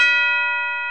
TUB BELL B3.wav